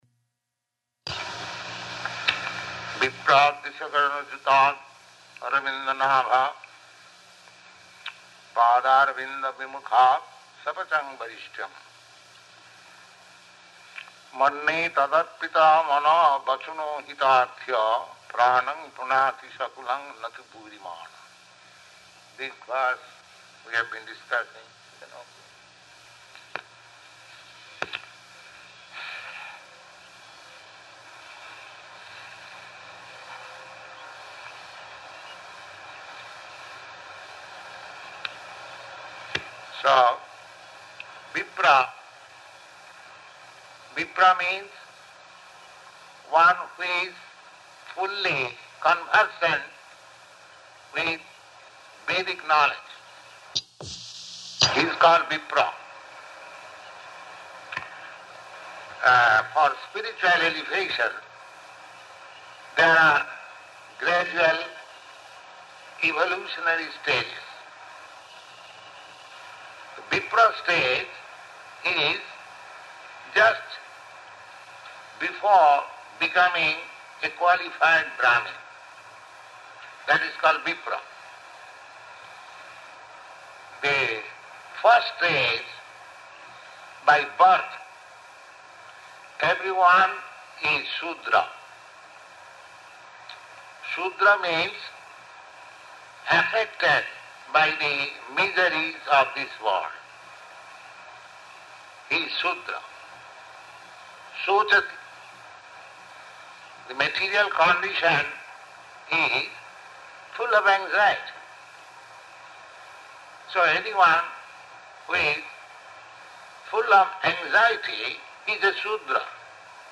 Type: Srimad-Bhagavatam
Location: Montreal